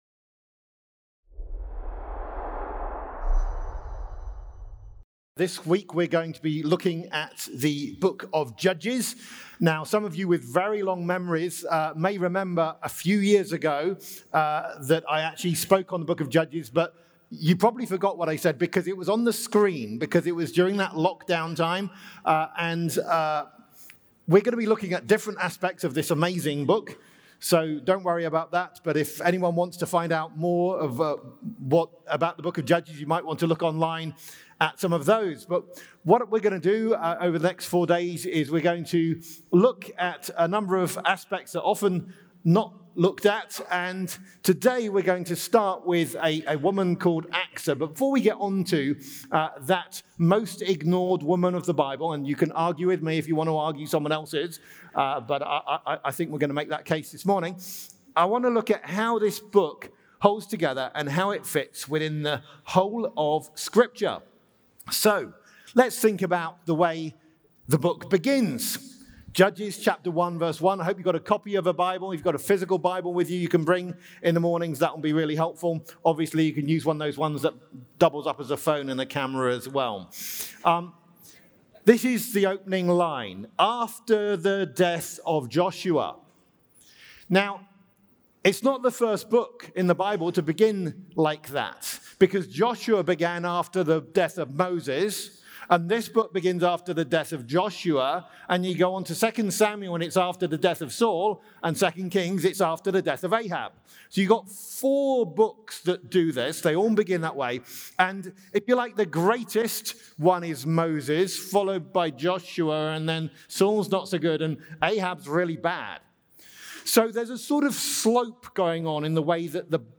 Event: ELF Plenary Session